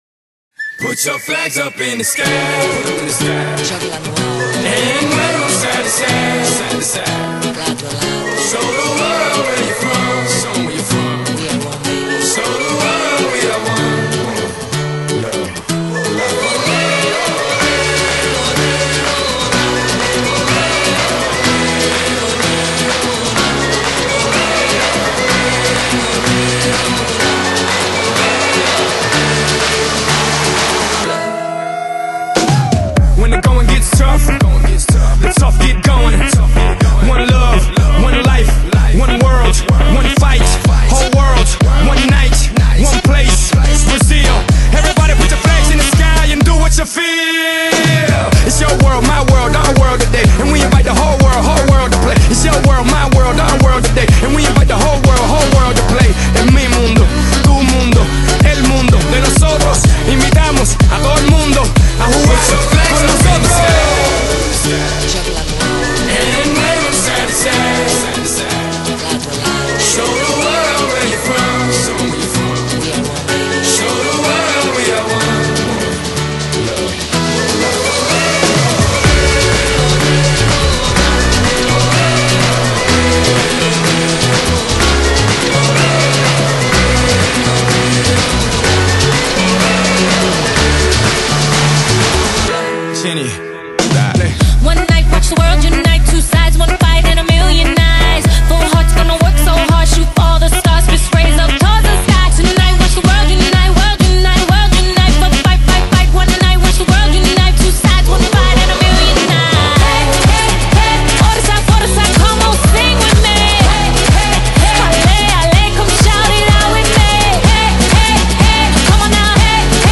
將Electro-Hop融入森巴的熱情節拍裡頭